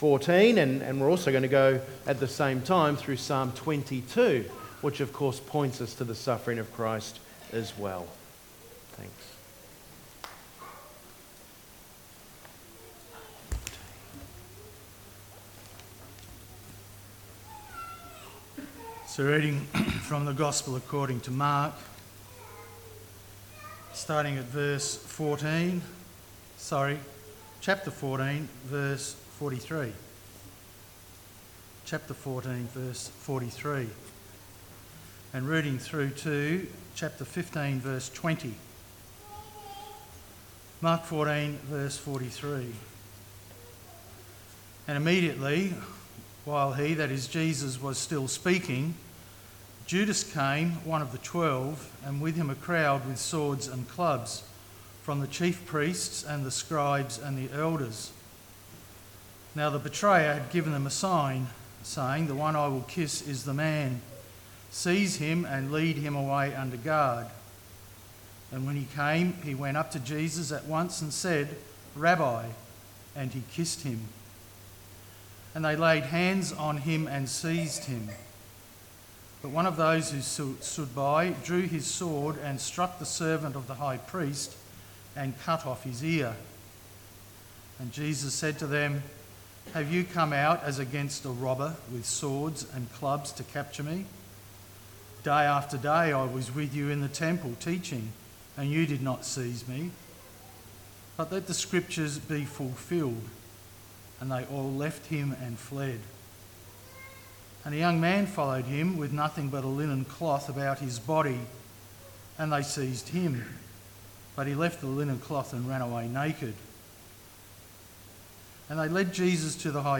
15-Apr-22.Good-Friday.mp3